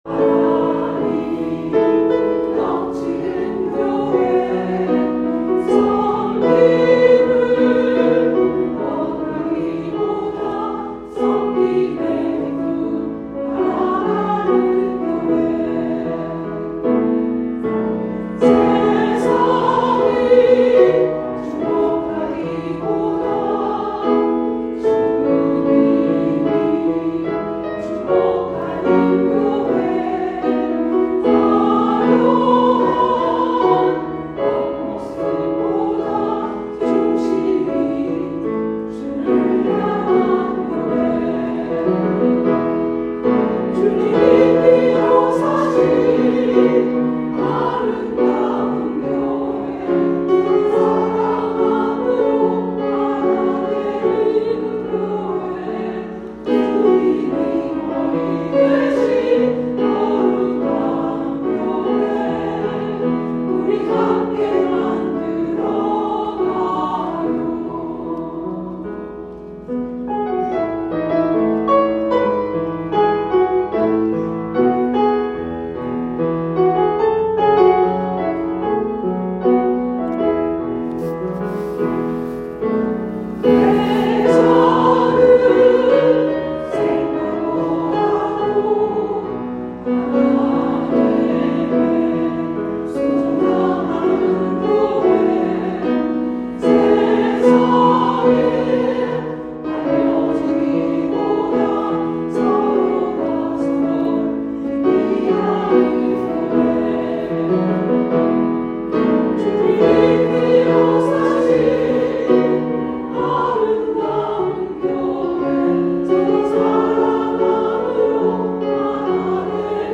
찬양대 Rejoice & Jublilee Choir
2023년 7월 30일 쥬빌리 찬양대 ‘교회‘